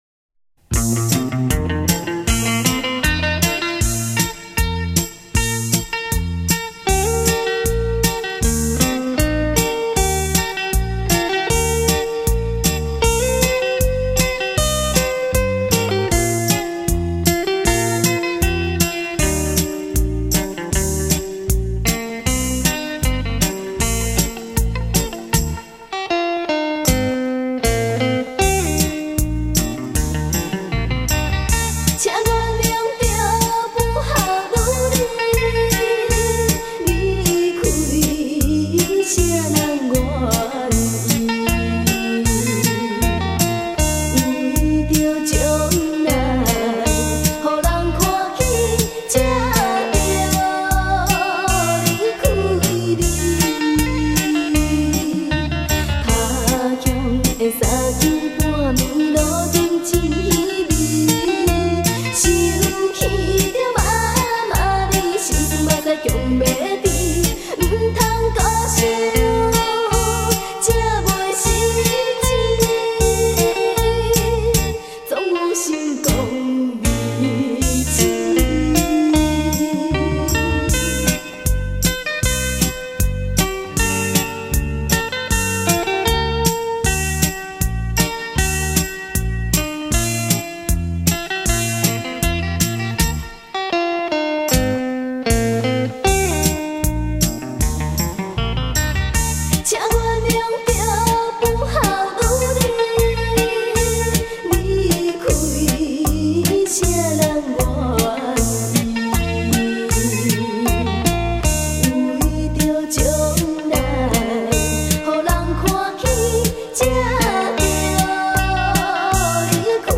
悠扬双吉他声中
悠扬的乐声